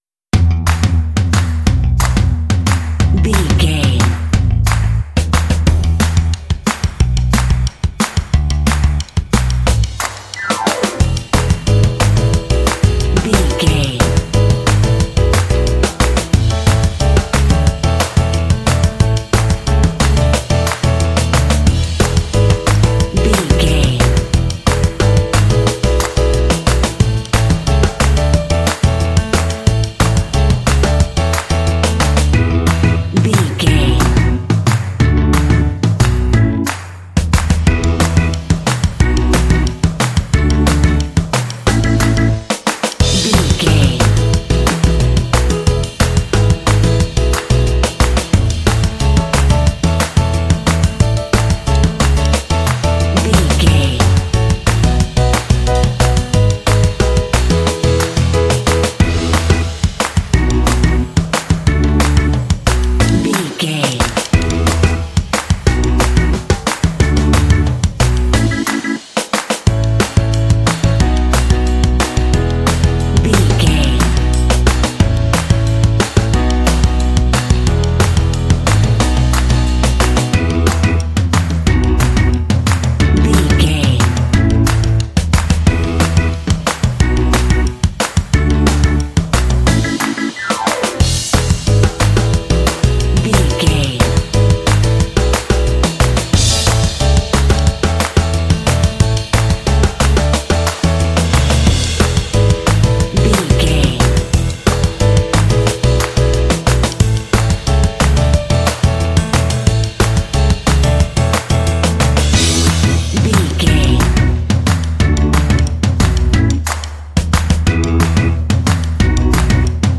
Ionian/Major
Fast
cheerful/happy
lively
energetic
playful
drums
bass guitar
piano
electric organ
alternative rock